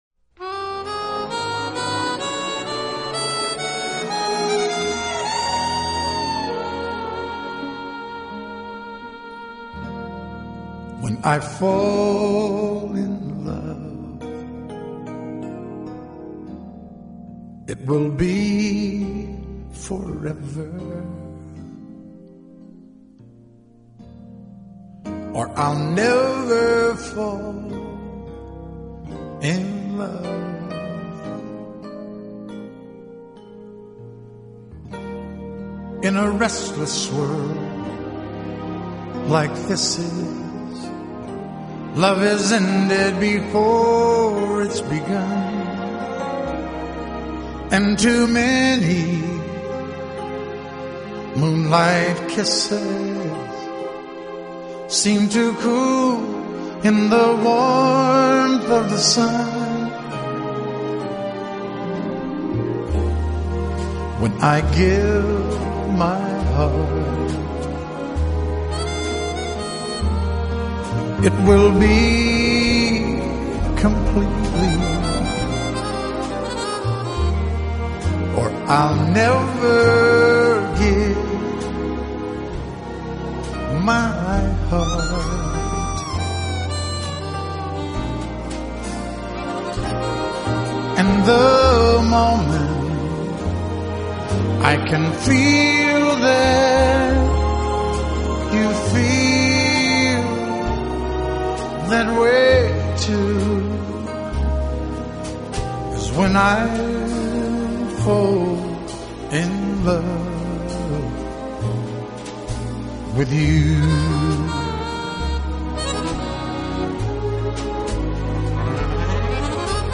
【乡村歌曲】
往往把乡村歌曲和城市轻柔摇滚糅合在一起，使流行歌曲也带有浓郁的乡土气息。